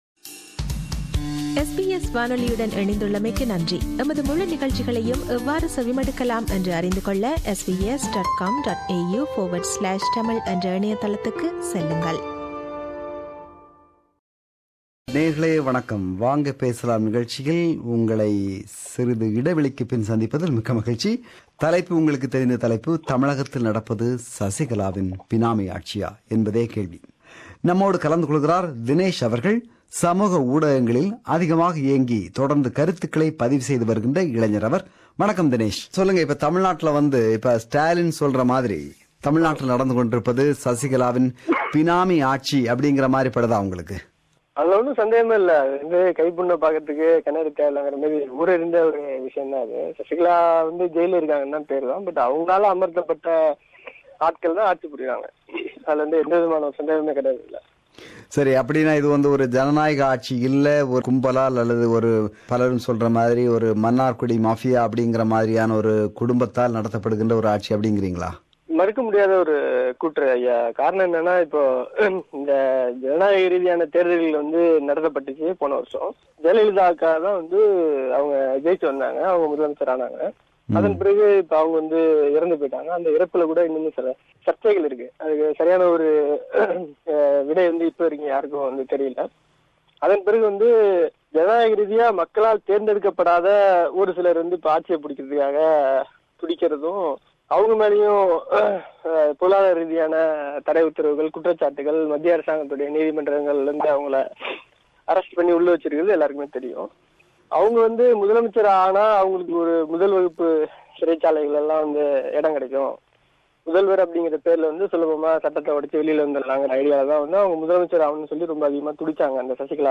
Do you agree to this view? This is the compilation of the comments shared by our listeners